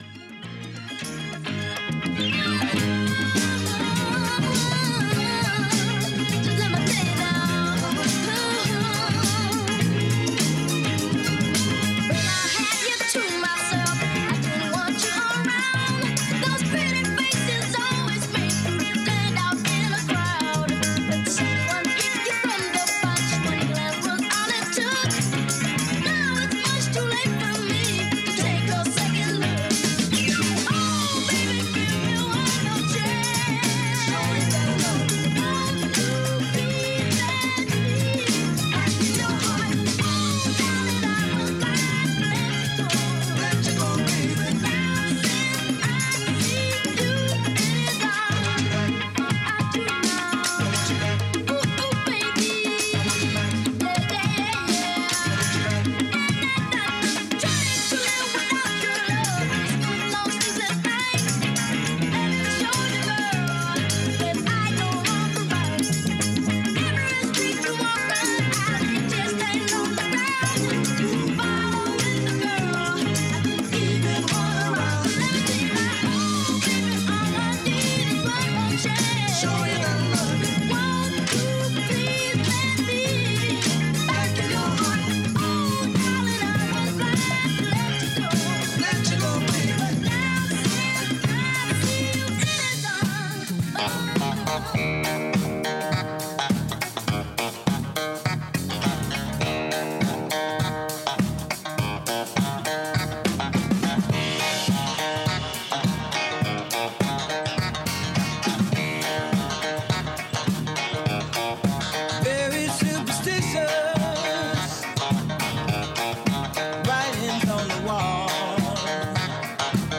A live wedding set